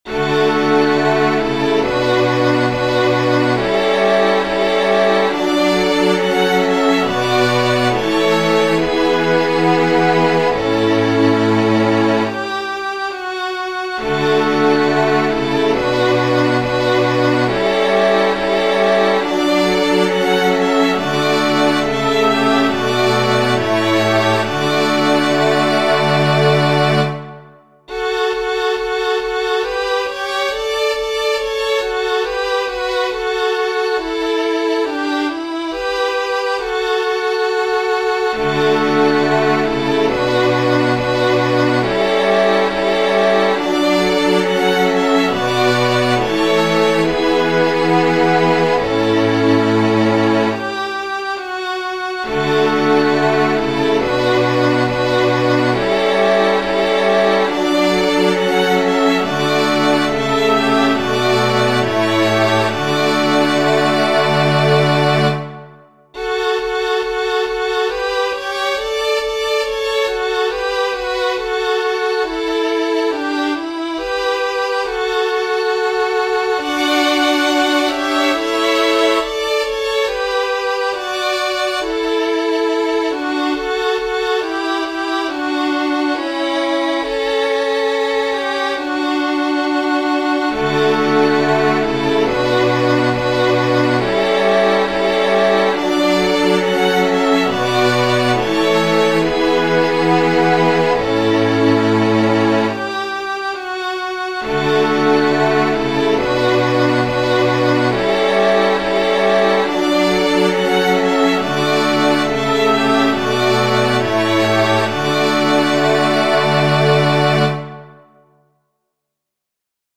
• Catégorie : Chants d’Acclamations.